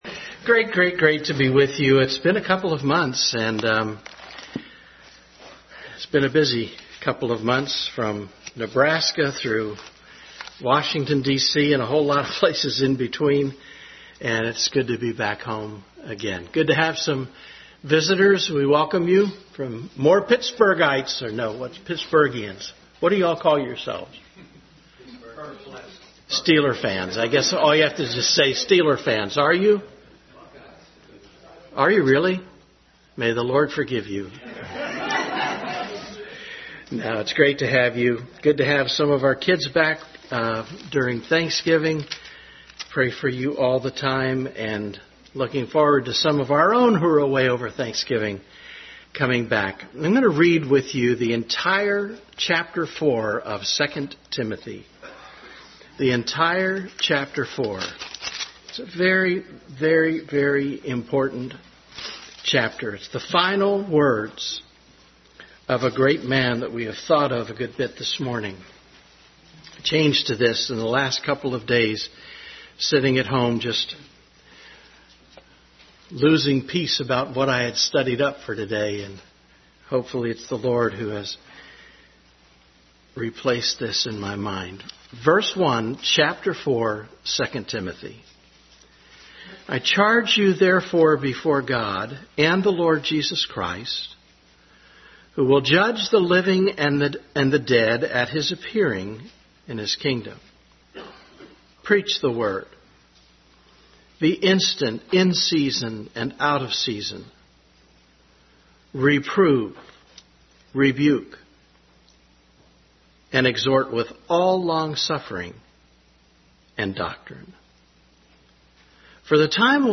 2 Timothy 4 Service Type: Family Bible Hour Bible Text